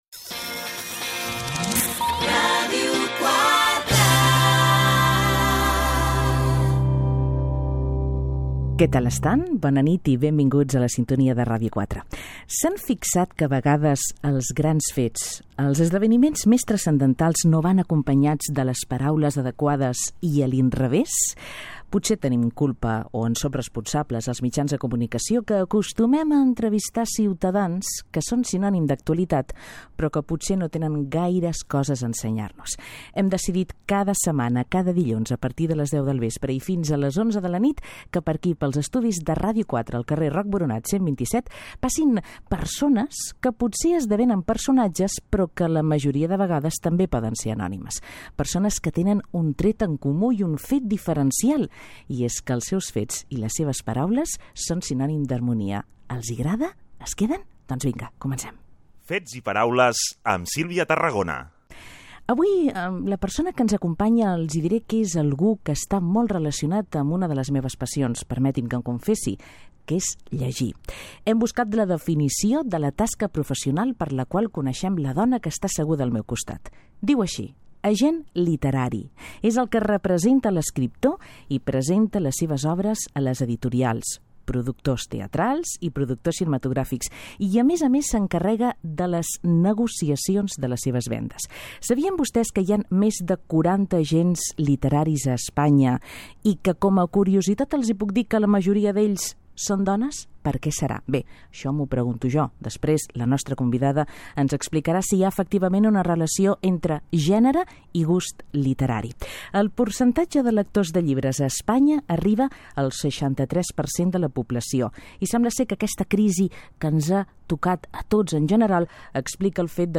Indicatiu de la ràdio
la feina de l'agent literari, tema musical
Entreteniment